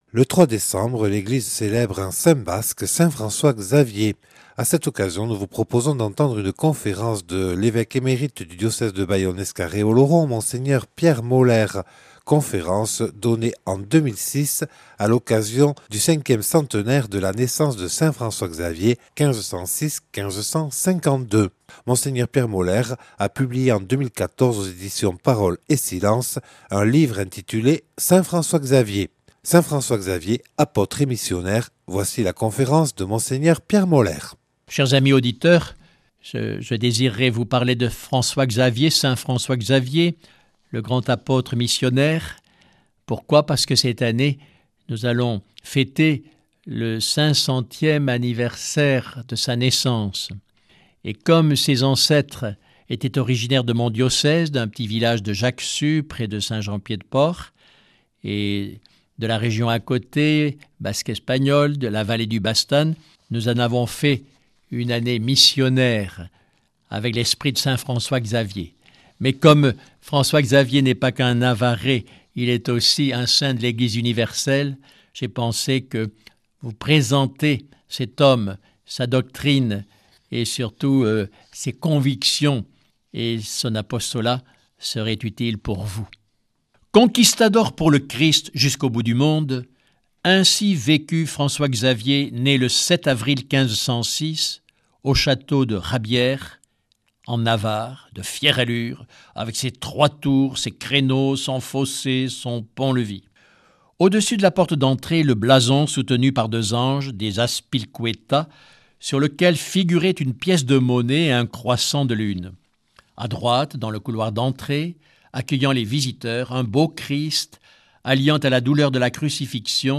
Conférence de Mgr Pierre Molères, évêque émérite de Bayonne.
(Enregistrée en 2006 lors du 5ème centenaire de la naissance de Saint-François-Xavier).